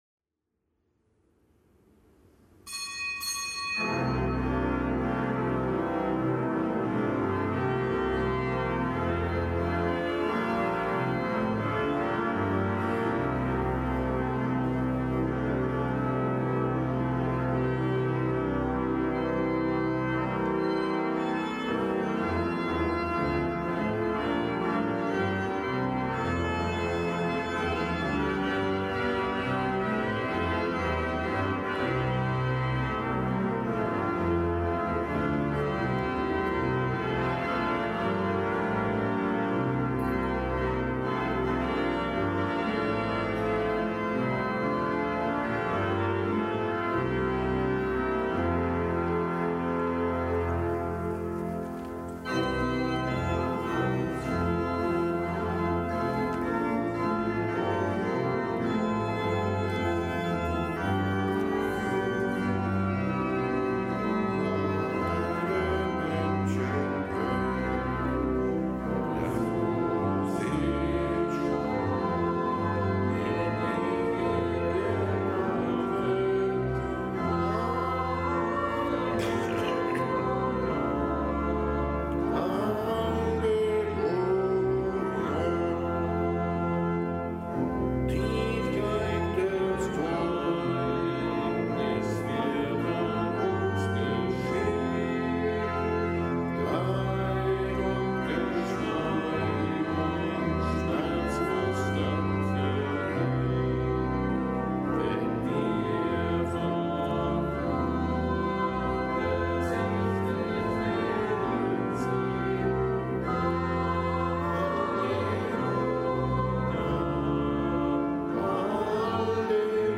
Kapitelsmesse aus dem Kölner Dom am Mittwoch der vierunddreißigsten Woche im Jahreskreis. Zelebrant: Weihbischof Dominikus Schwaderlapp.